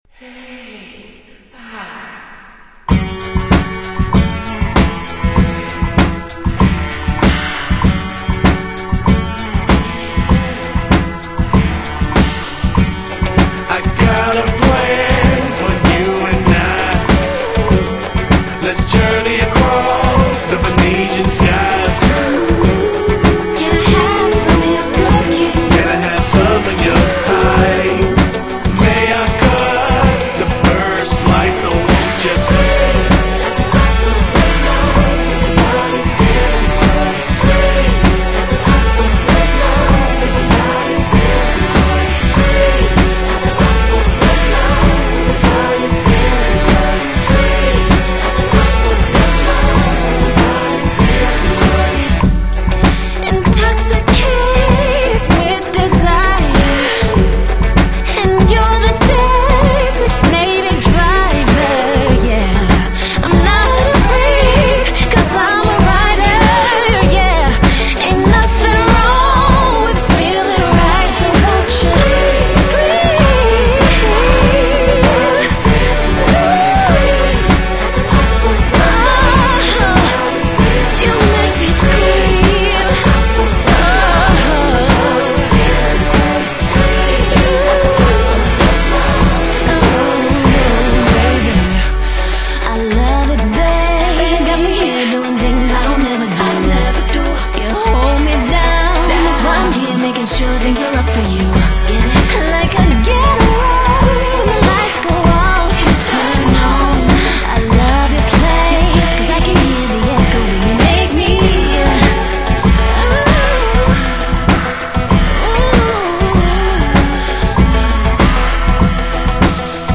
Жанр:Hip-Hop,Rap,R&B,Pop,Rock...